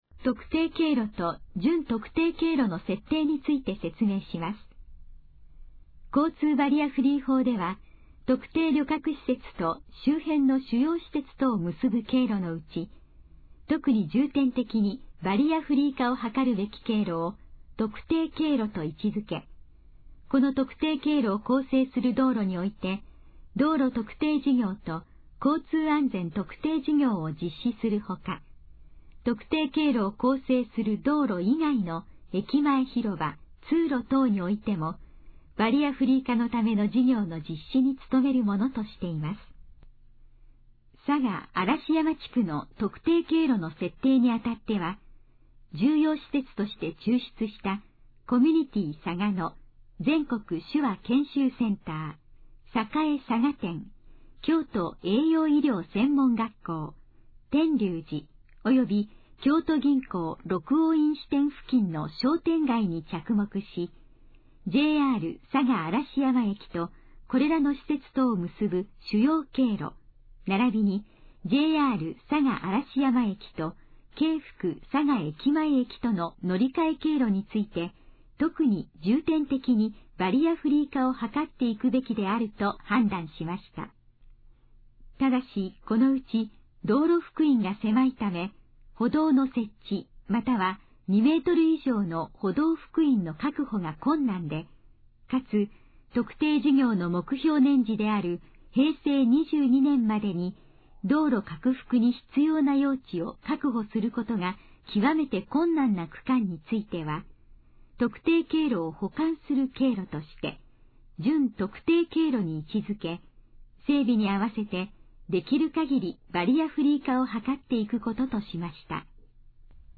以下の項目の要約を音声で読み上げます。
ナレーション再生 約291KB